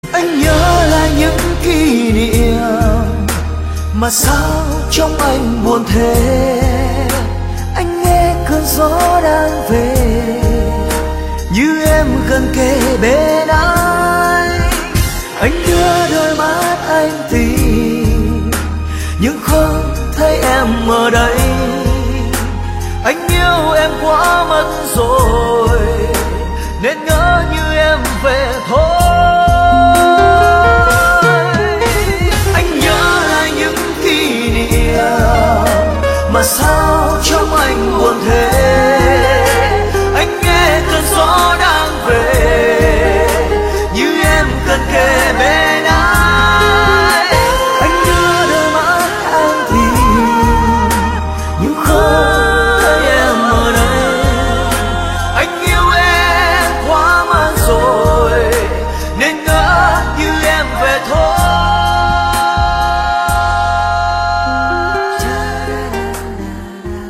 Thể loại Nhạc Trẻ - Chất lượng lossless